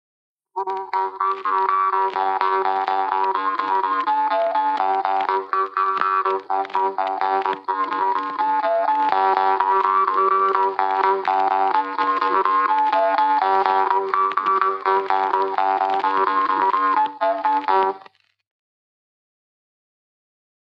Labajalg "Allmetsa All Andrus"